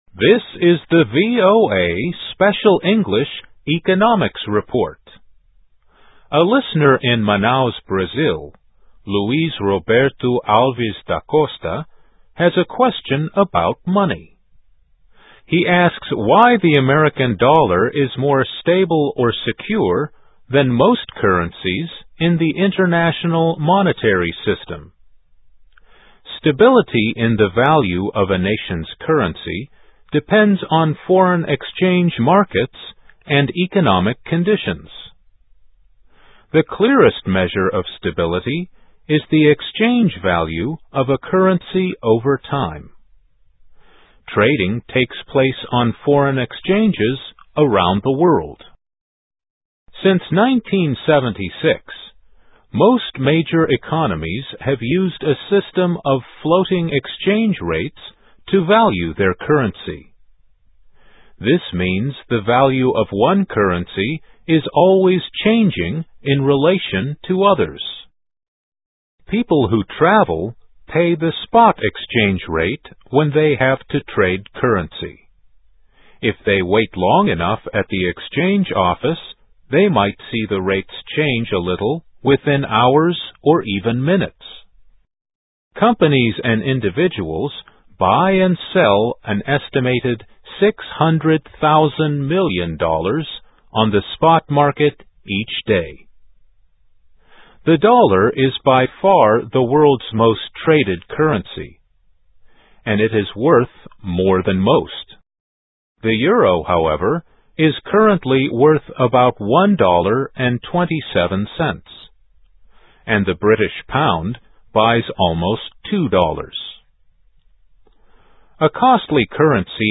Dollar Is World's Most Traded Currency. But Why? (VOA Special English 2006-10-26)
Voice of America Special English